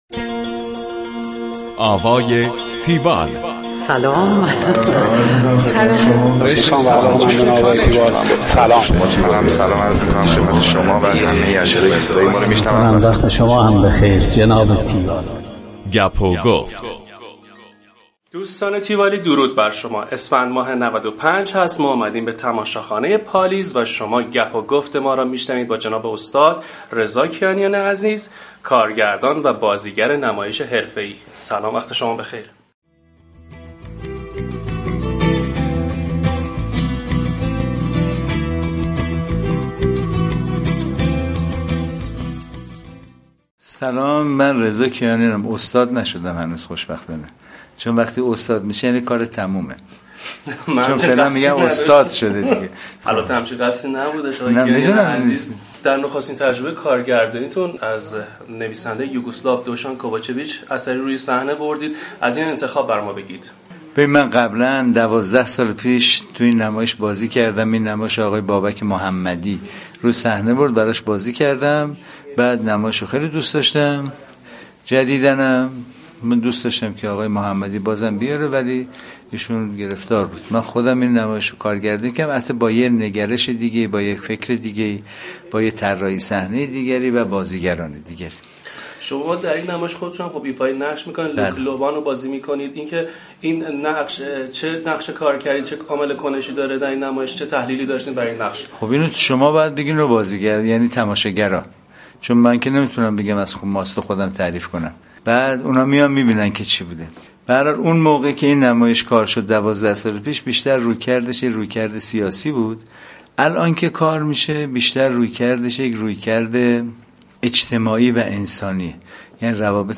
tiwall-interview-rezakianian.mp3